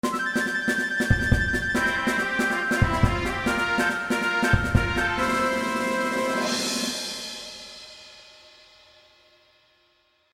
And both seem a tad quiet.